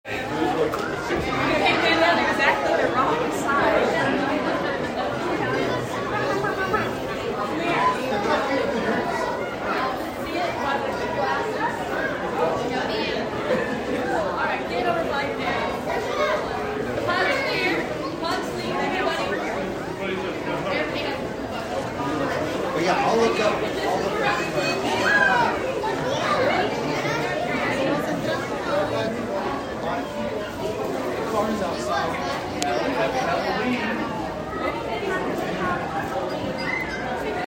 While Boo in the Zoo was ongoing, across town Flint Hills Mall was another destination for local trick-or-treaters to fill up on candy.
mall-o-ween-nat-1.mp3